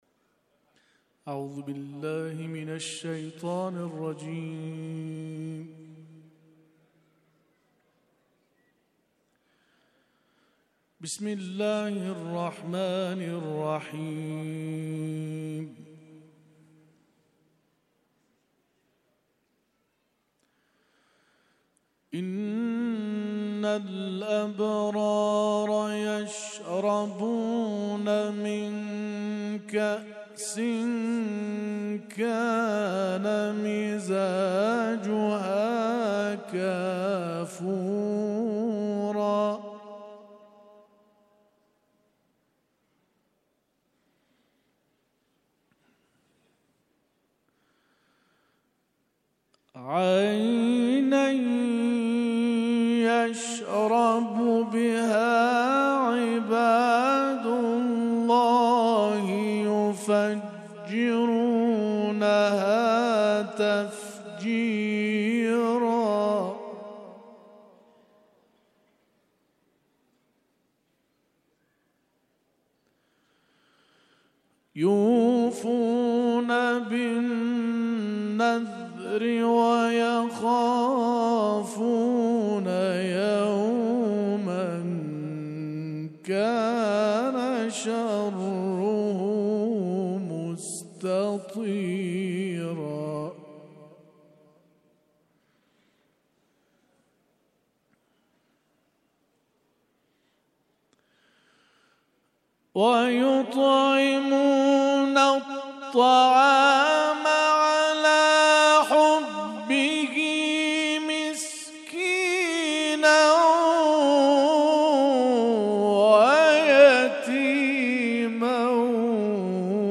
تلاوت ظهر
تلاوت قرآن کریم